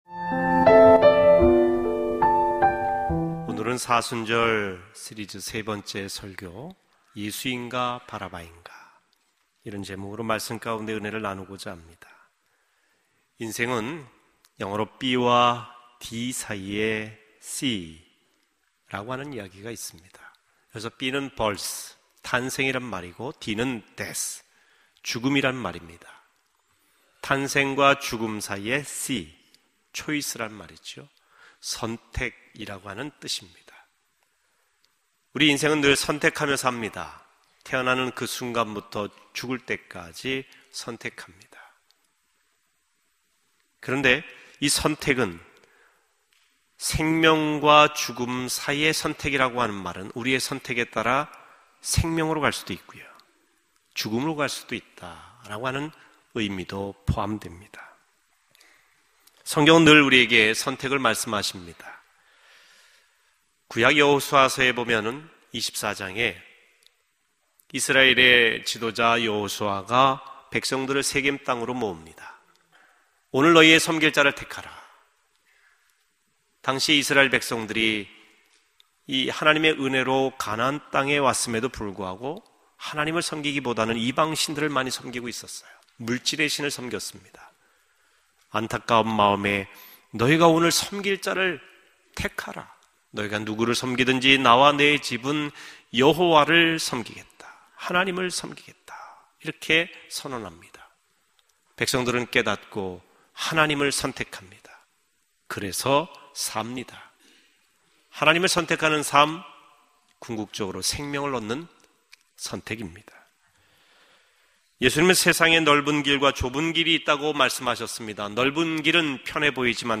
설교방송